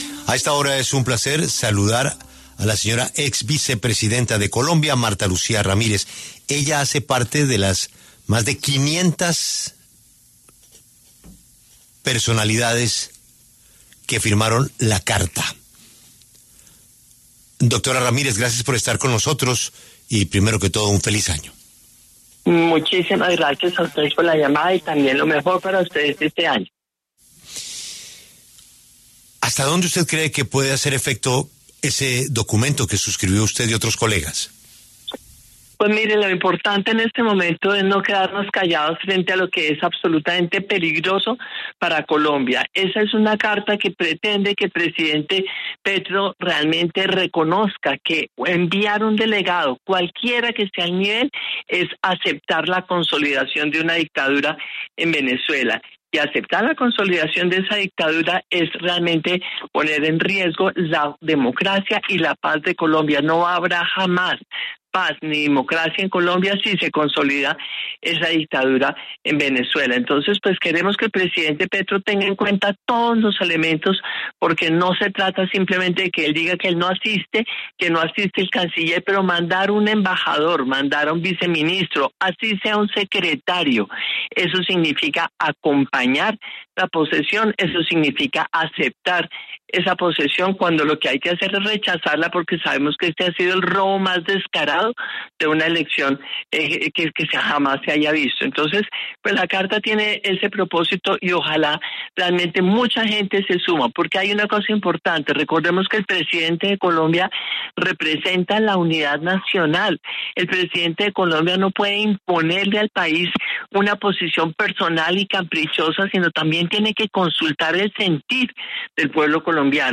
La ex vicepresidenta Marta Lucía Ramírez y la senadora Sandra Ramírez se refirieron en La W a la carta en la que 500 figuras públicas rechazan la asistencia de Colombia a la posesión de Nicolás Maduro como presidente de Venezuela.